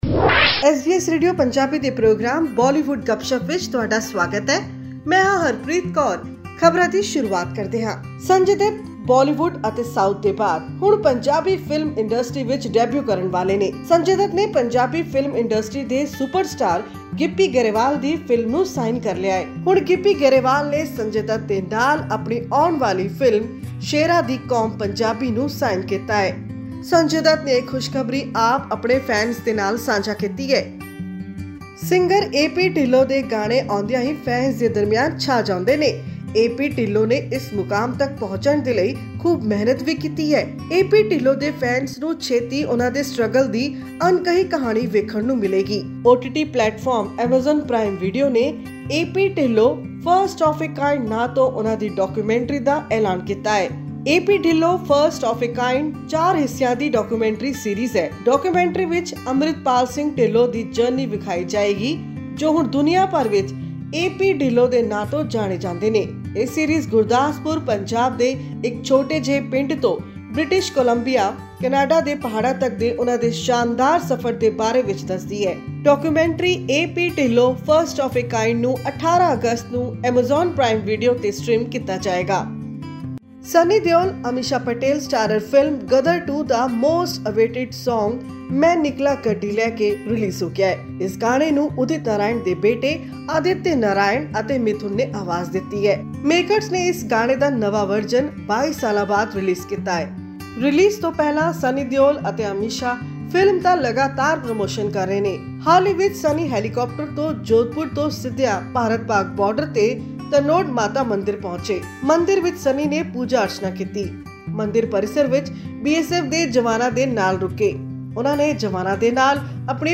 Abhishek Bachchan and Saiyami Kher starrer sports drama 'Ghoomer' had a dazzling world premiere at the Indian Film Festival of Melbourne 2023. This and more in our weekly news segment of Bollywood Gupshup on upcoming movies and songs.